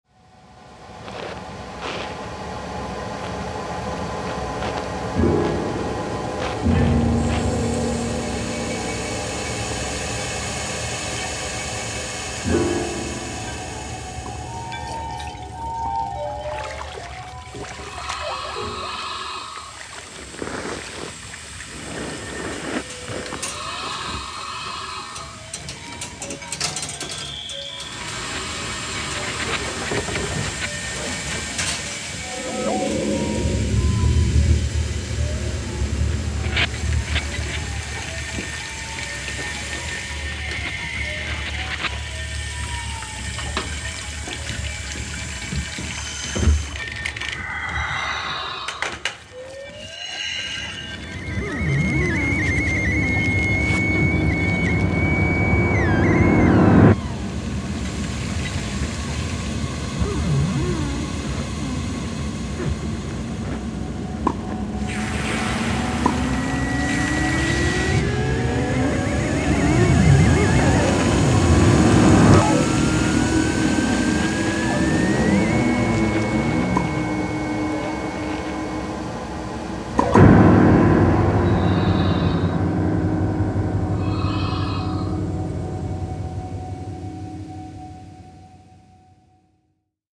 Genre: acousmonautics.